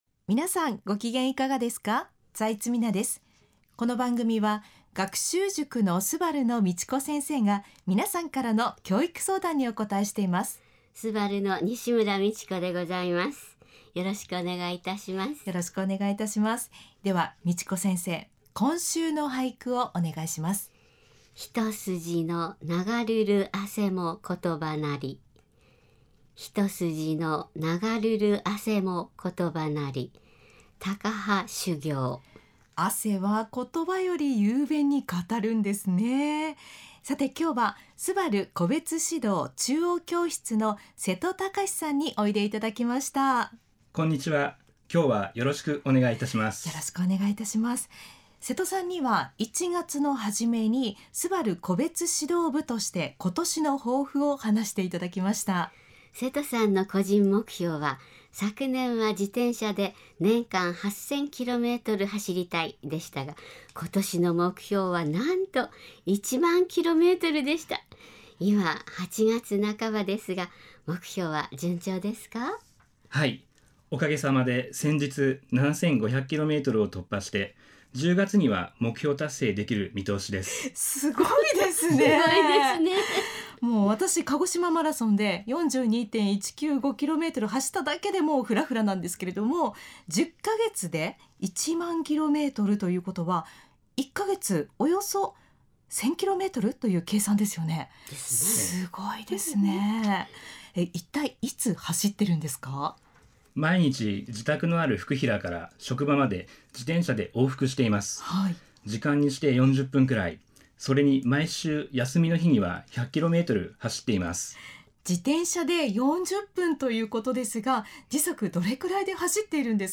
今週はゲストにお越しいただきました。